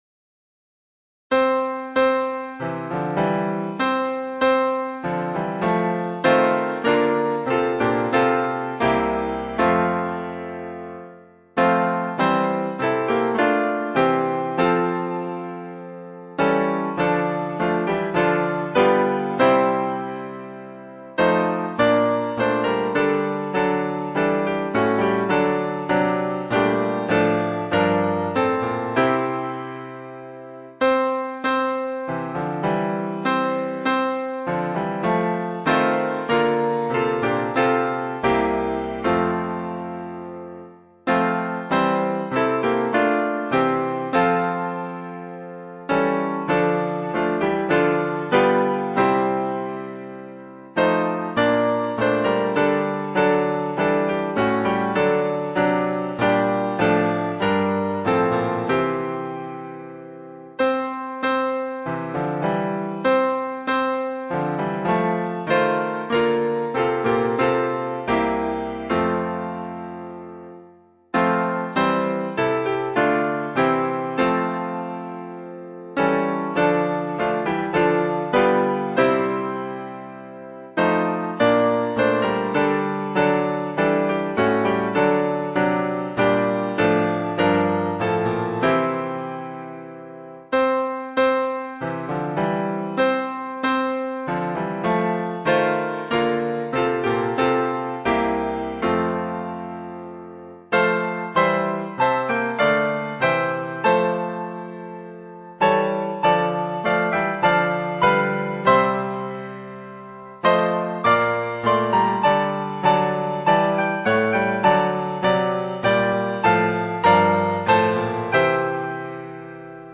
导读：本颂赞诗歌歌谱采用2017年修订版，录音示范暂用旧版，将逐渐更新。